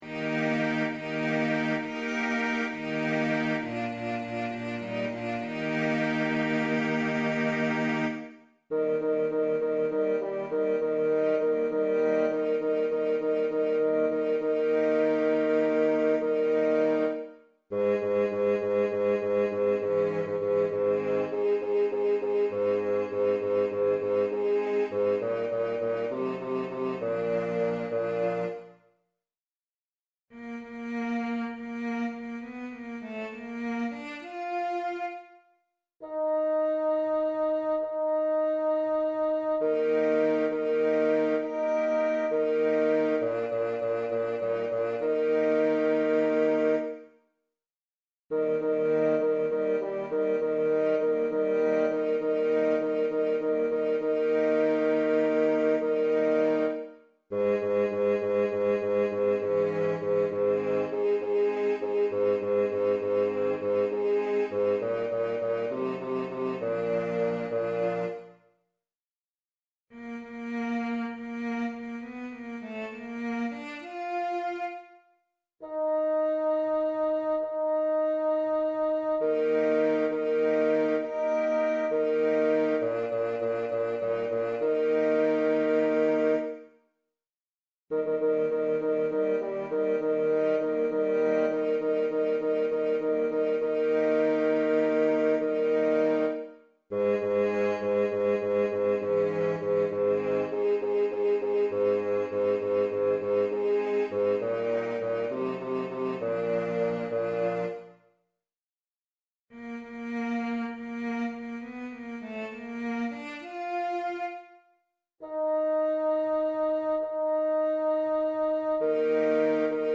Lützows wilde Jagd Bass 2 als Mp3
luetzows-wilde-jagd-einstudierung-bass-2.mp3